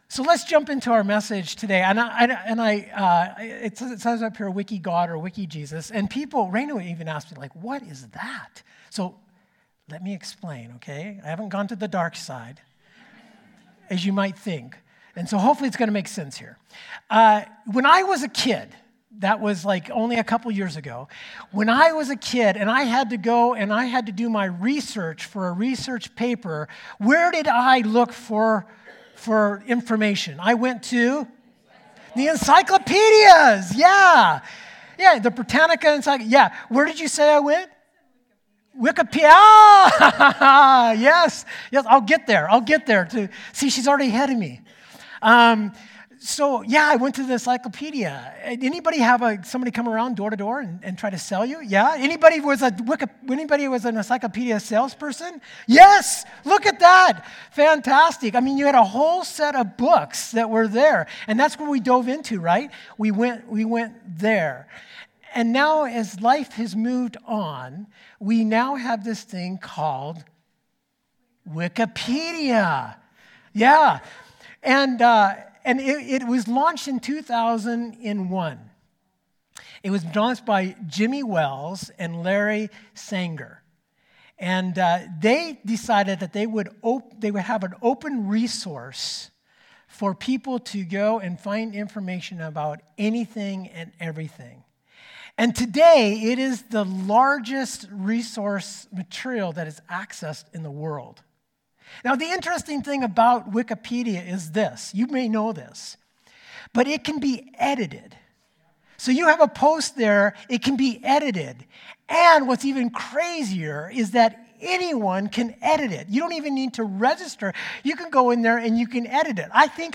Sermons | Oceanview Community Church